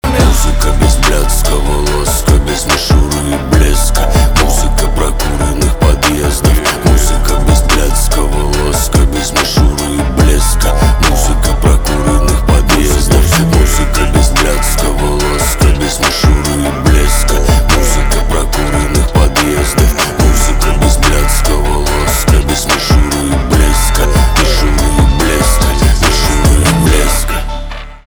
русский рэп
жесткие , крутые , битовые , басы